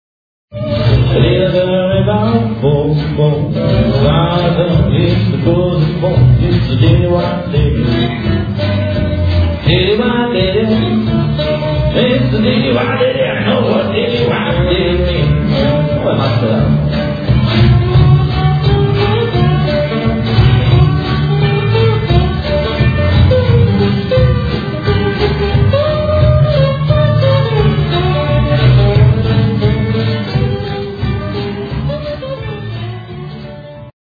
BLUES
Live from Lipnice [2001].